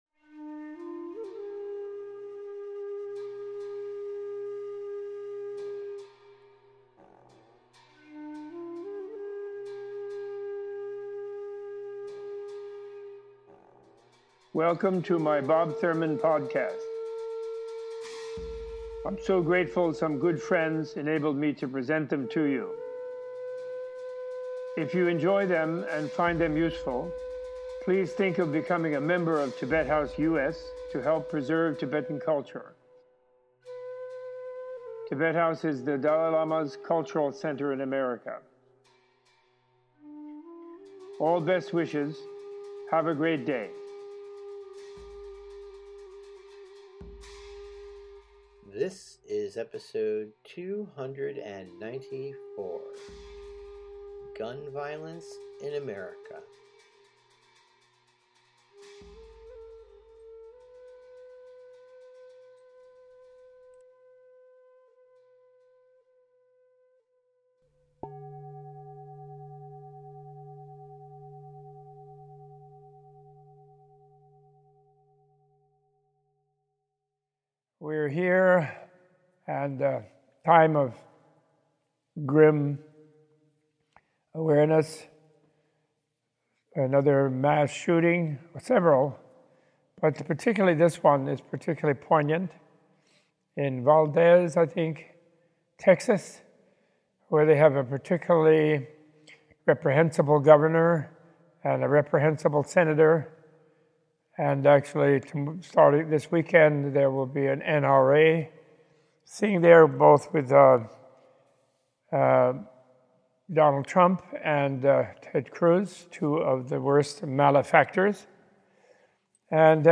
In this episode Robert Thurman gives an impassioned and direct call to action for Americans to immediately address the decades of school shootings and senseless gun violence happening across the country, highlighting the forces behind the political deadlock preventing sensible legislation as well as simple direct democratic ways of creating a safer and better future for those on both side of the debate.